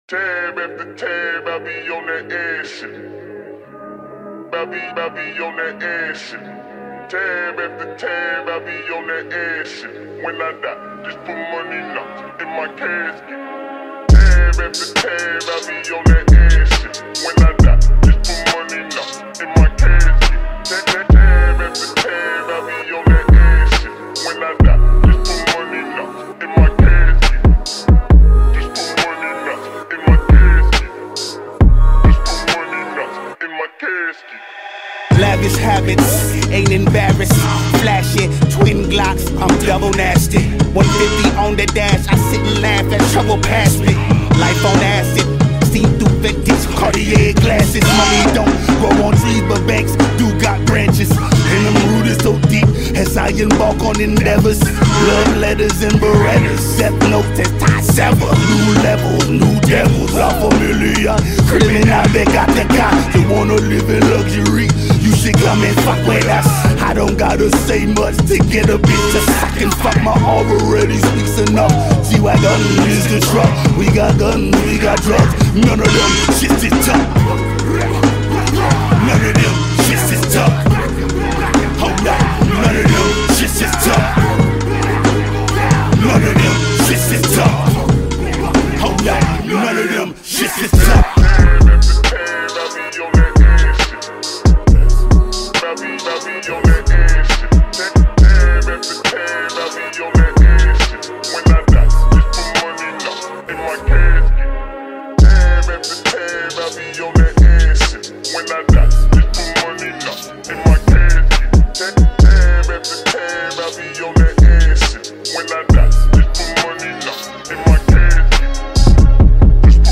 ” offering smooth vocals
rich production